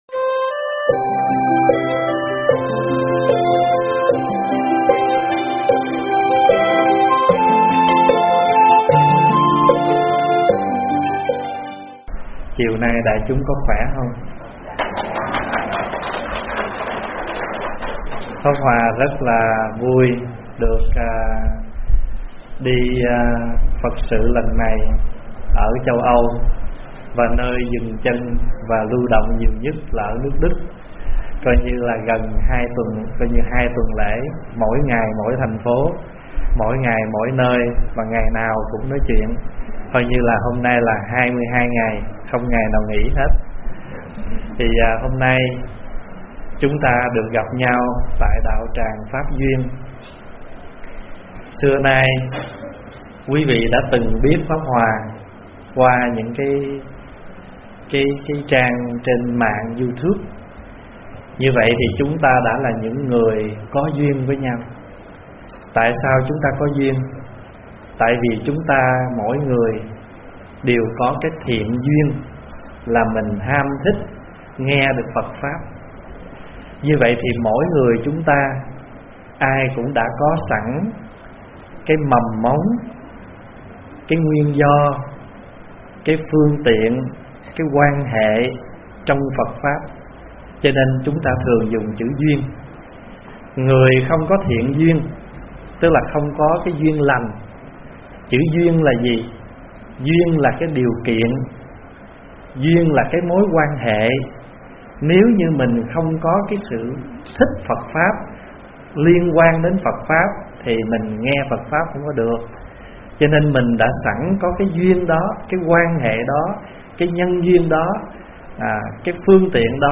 Mp3 vấn đáp Kết Duyên Đầu Năm